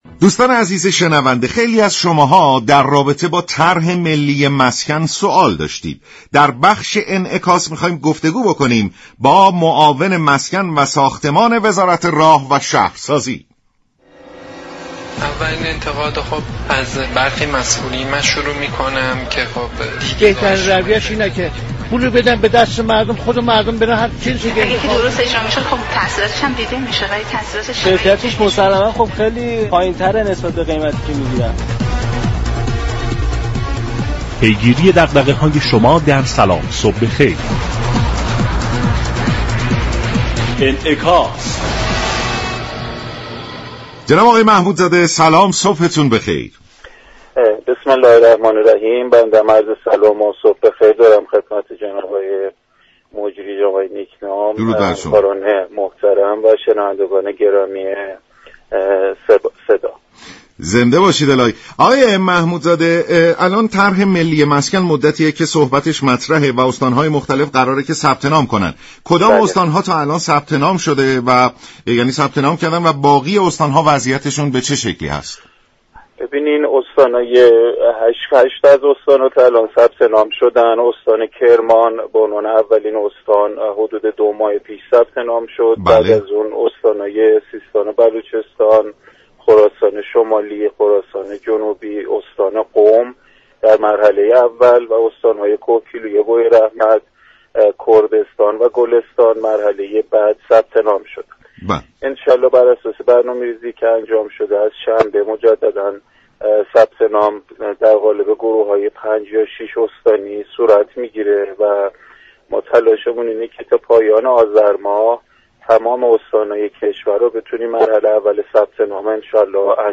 معاون مسكن و ساختمان وزارت راه و شهرسازی در گفت و گو با رادیو ایران گفت: تلاش می كنیم مرحله نخست ثبت نام در طرح ملی مسكن تا پایان آذرماه در تمامی استان های كشور اجرا شود.